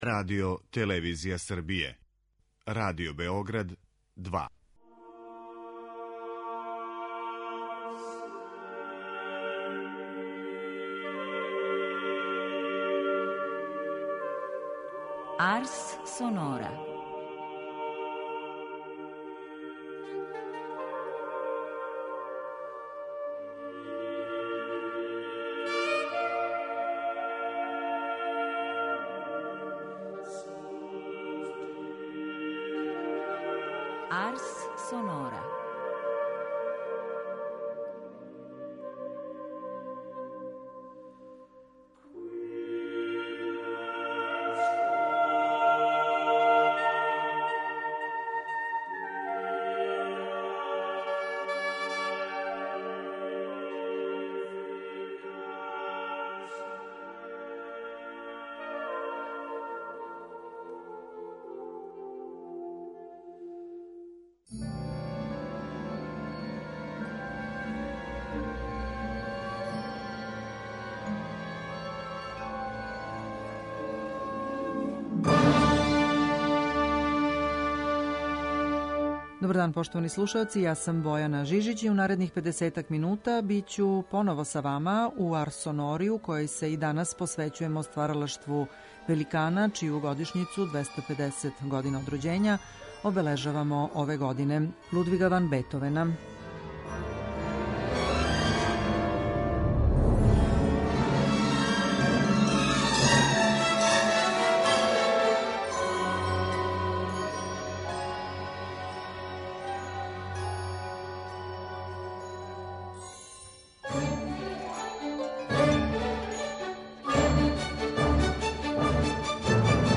У емисији Арс сонора настављамо да обележавамо јубилеј Лудвига ван Бетовена ‒ 250 година од рођења, уз његове клавирске сонате које свира руско-немачки пијаниста Игор Левит. У данашњој емисији овај изузетни солиста извешће нам три његове сонате из опуса 10: бр.1 у це-молу, бр. 2 у Еф-дуру и бр. 3 у Де-дуру.